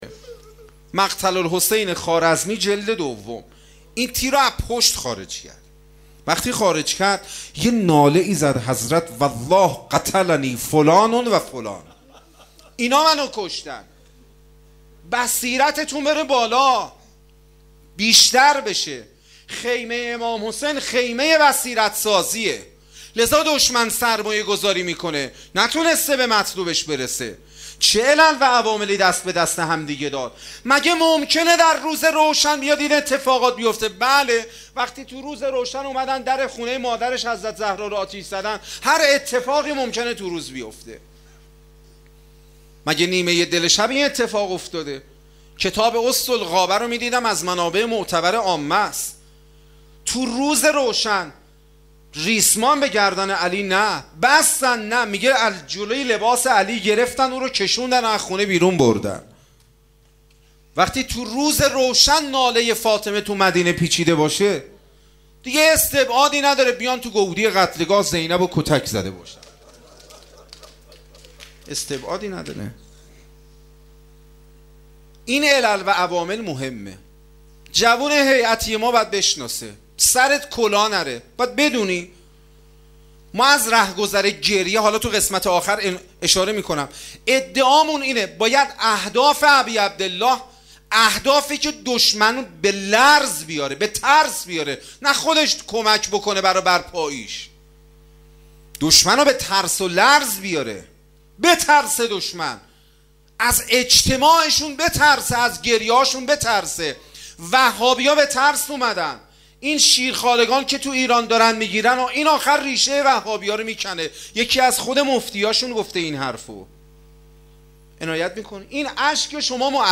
5 ارديبهشت 93_هيئت الرضا_سخنرانی_بخش دوم